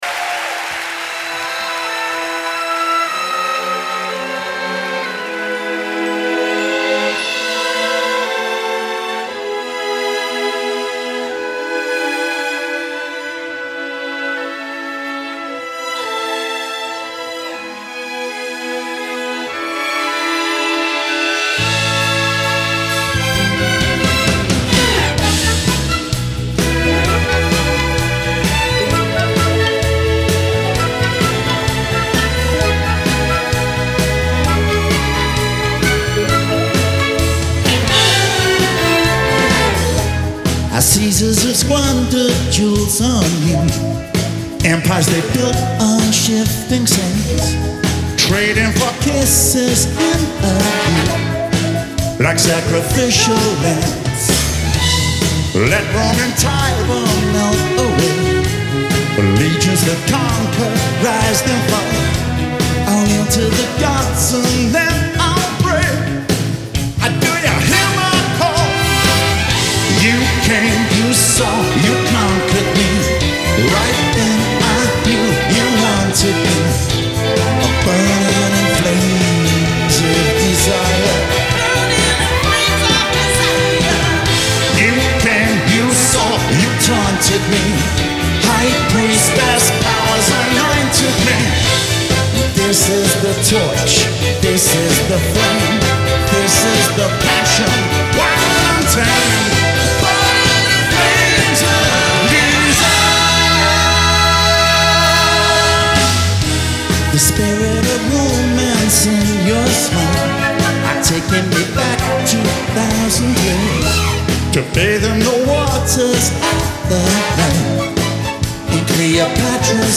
Hi-Res Stereo
Genre : Pop